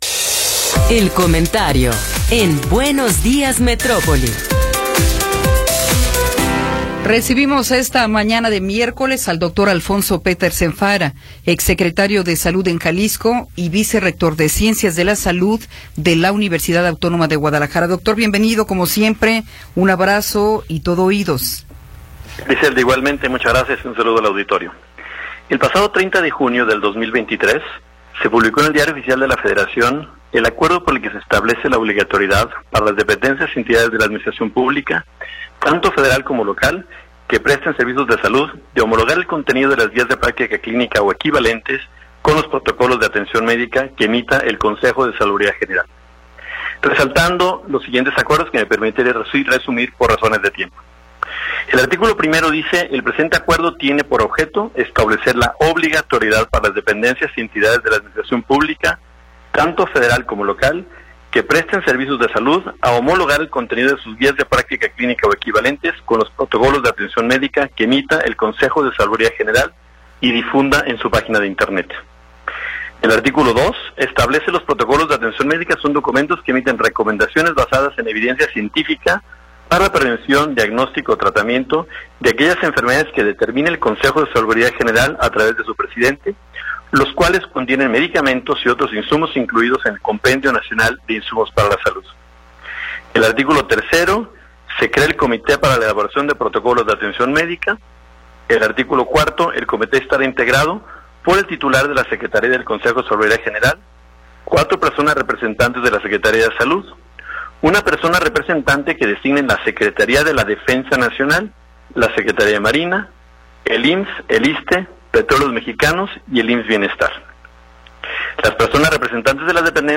Comentario de Alfonso Petersen Farah